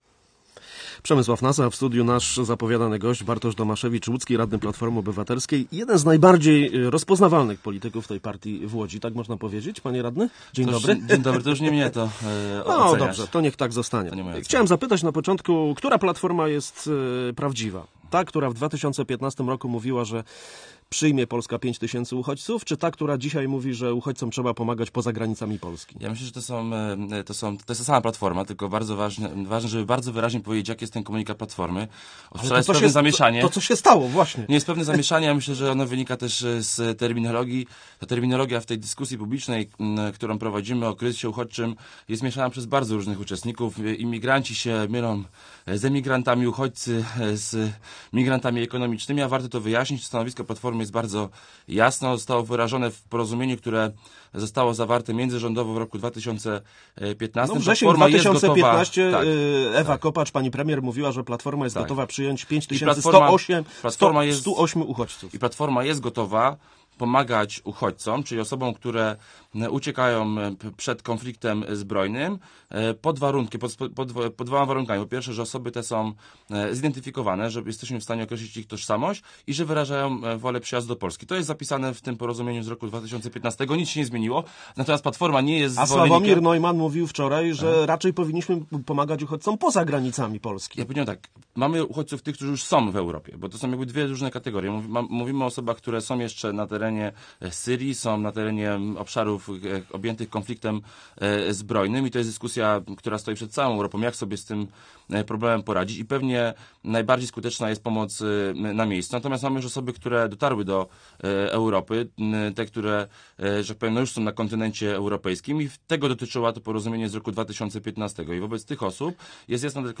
Gościem Radia Łódź był Bartosz Domaszewicz, łódzki radny Platformy Obywatelskiej.